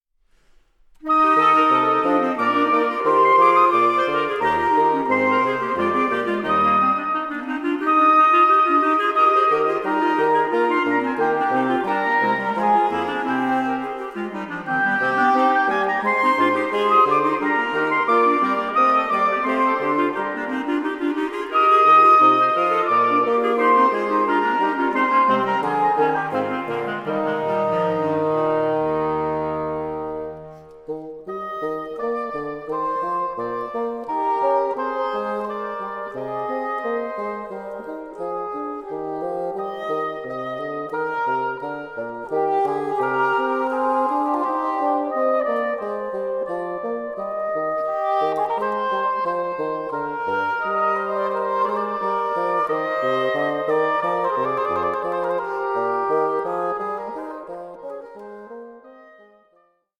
Dutch reed quintet
resulting in a many-coloured Christmas collage.